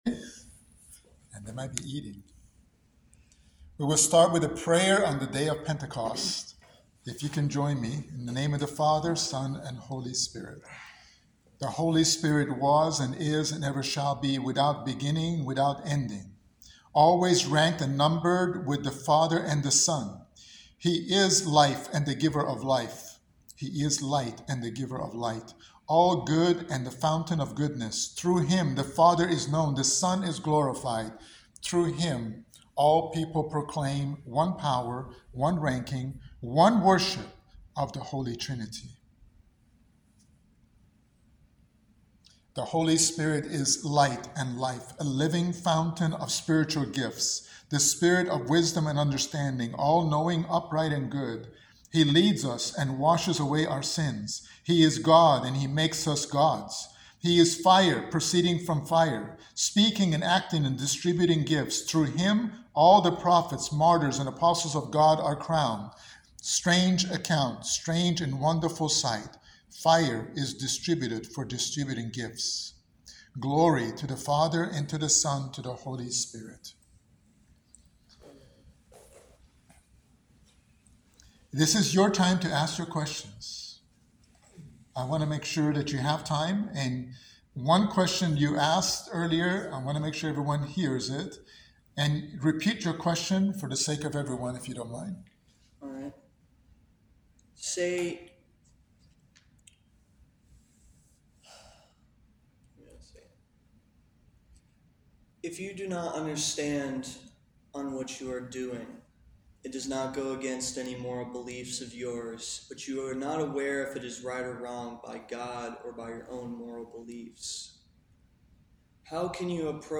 In this Orthodox Bible and adult study